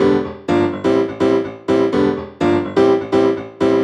cch_synth_joy_125_Gm.wav